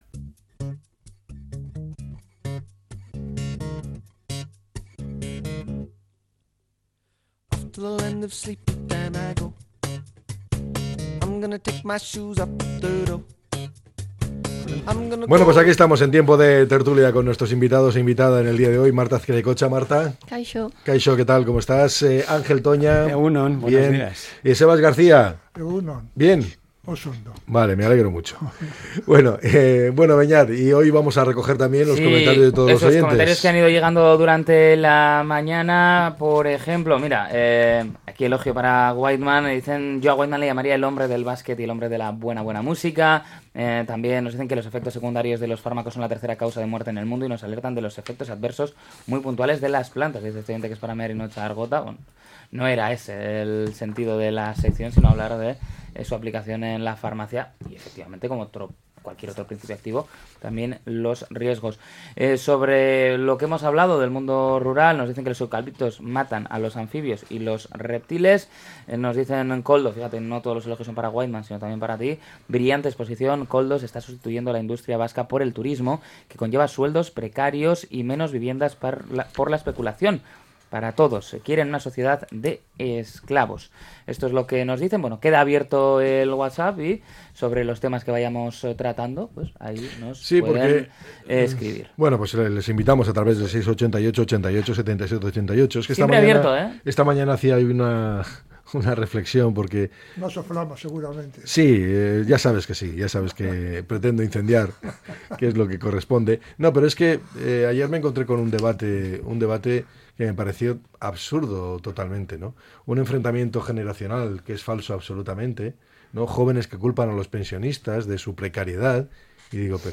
La tertulia 08-10-25.